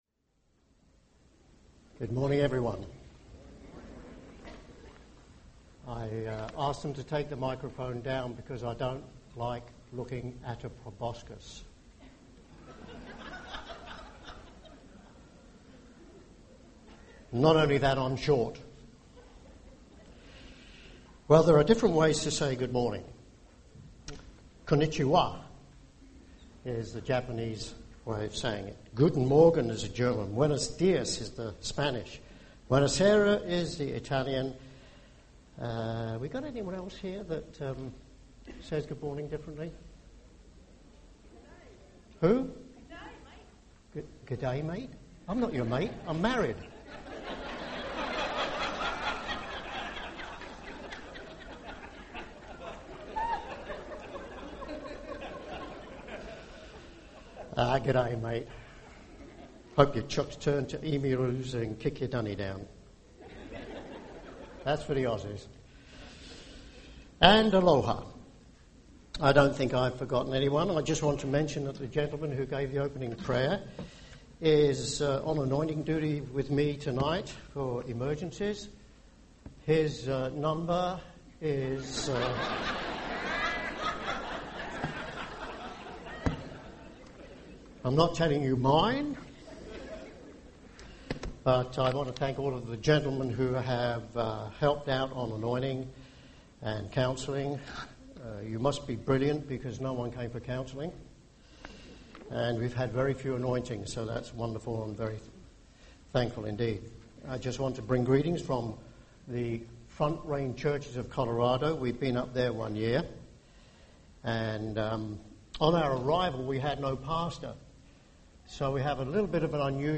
This sermon was given at the Maui, Hawaii 2011 Feast site.